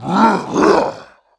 attack_1.wav